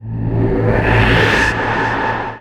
VEC3 FX Athmosphere 05.wav